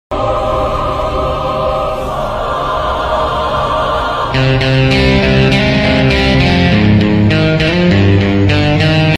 Banjo beat